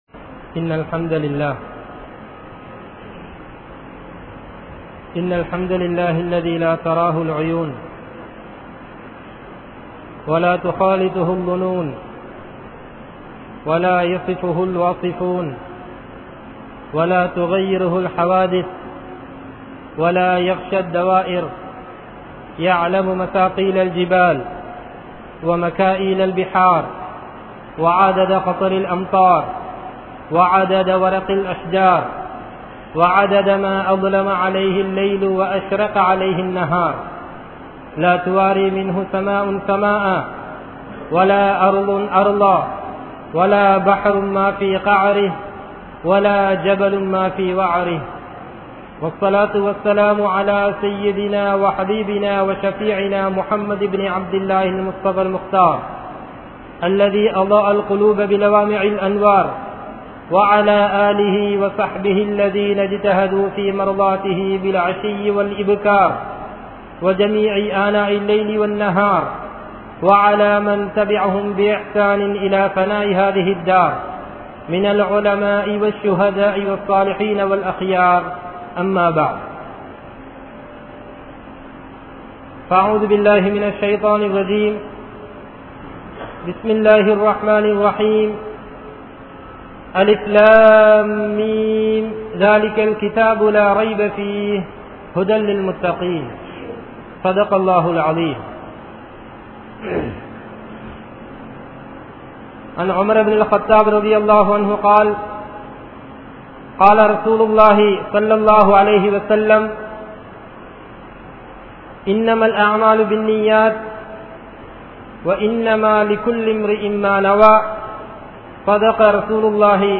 Naahareeham Entru Seeralium Samooham(நாகரீகம் என்று சீரழியும் சமூகம்) | Audio Bayans | All Ceylon Muslim Youth Community | Addalaichenai